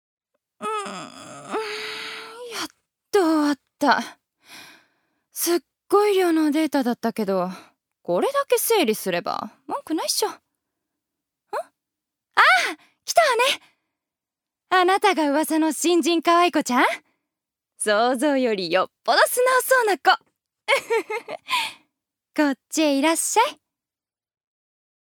預かり：女性
セリフ２